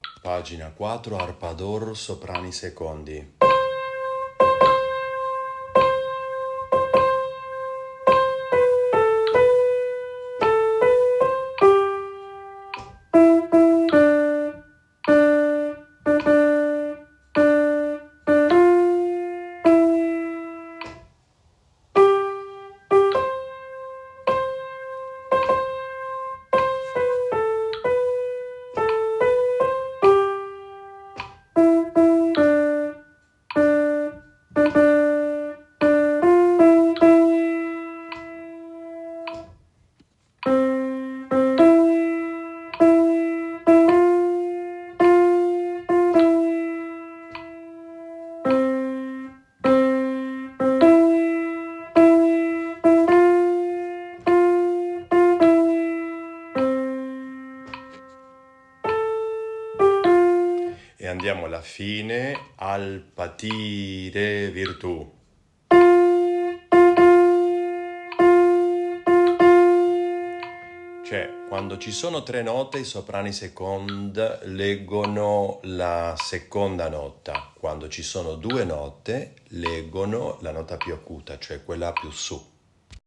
A.A. 24/25 Canto Corale